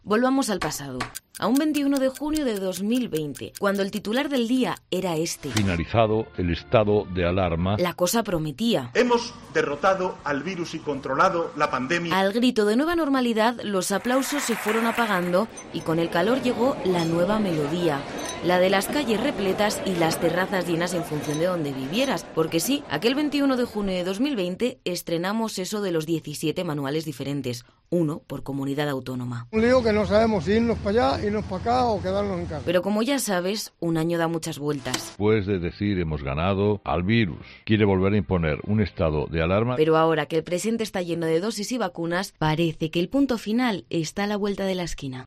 Un álbum sonoro de cómo vivimos el fin del primer estado de alarma